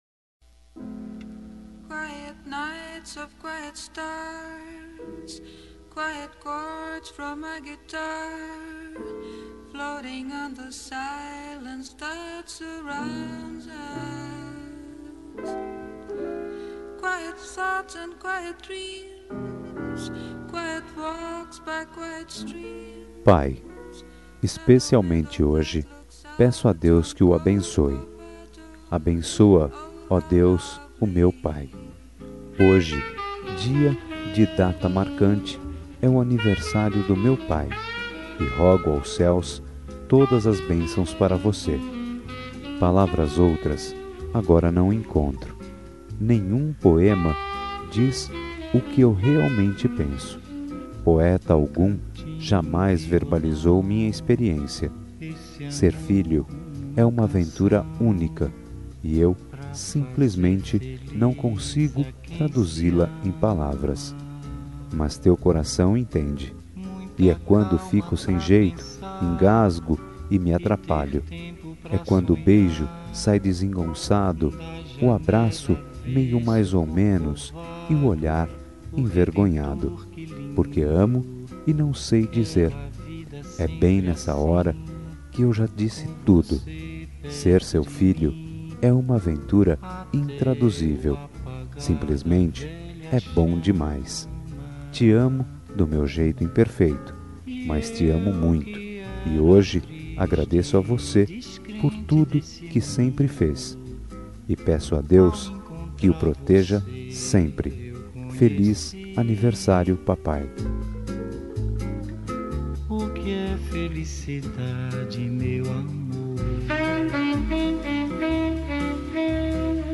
Aniversário de Pai – Voz Masculina – Cód: 11602